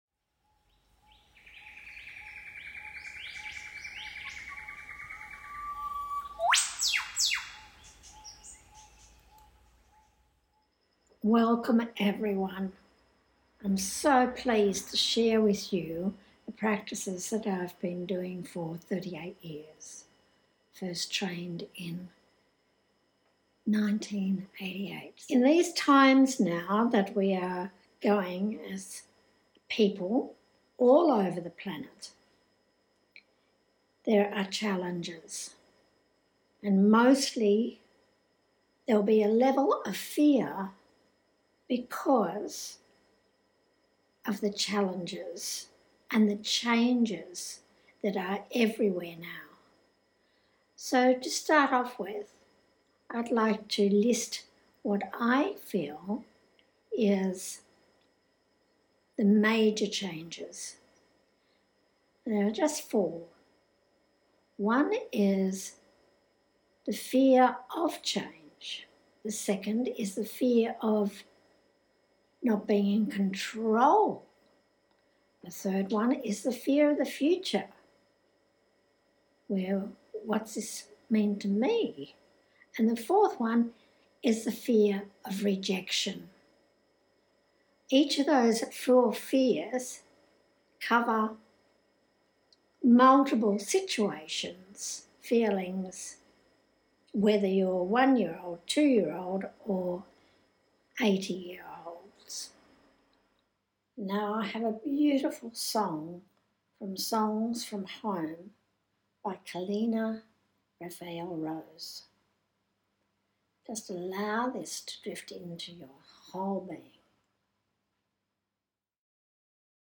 Deep breathing Inner Anxiety meditation process.